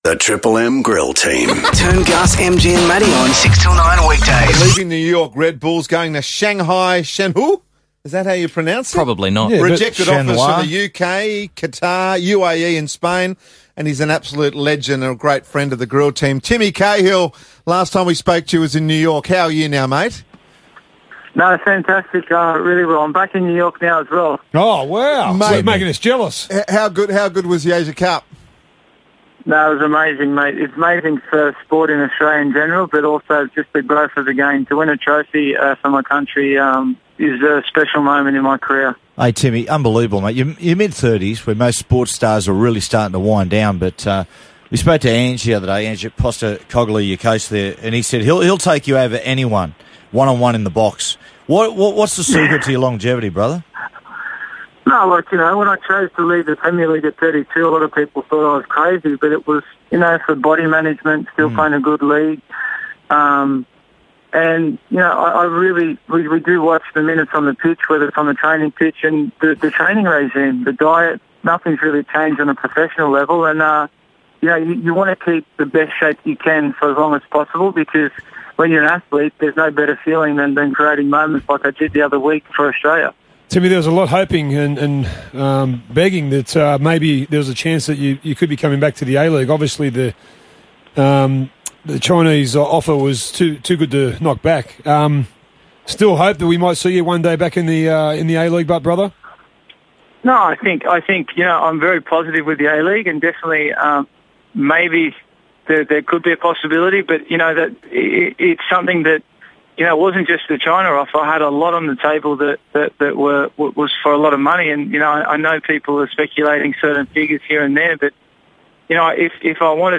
Tim Cahill speaks to the Triple M Grill Team